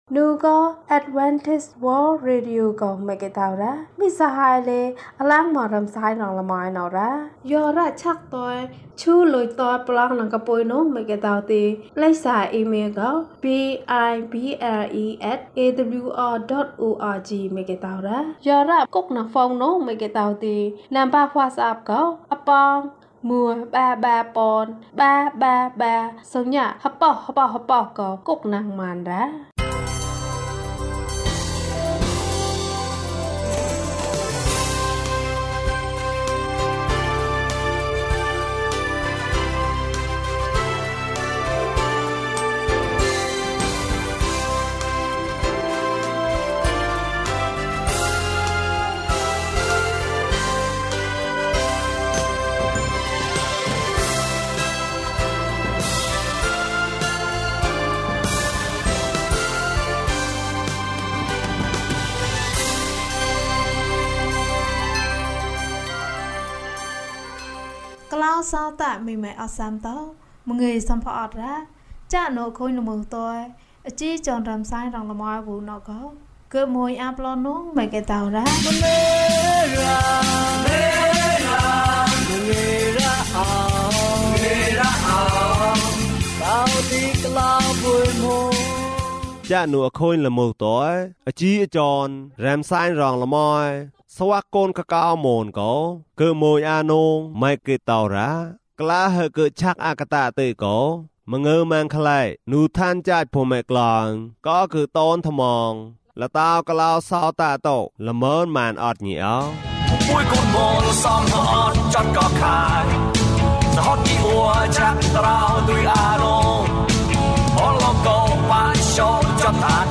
ခရစ်တော်ထံသို့ ခြေလှမ်း ၁၅။ ကျန်းမာခြင်းအကြောင်းအရာ။ ဓမ္မသီချင်း။ တရားဒေသနာ။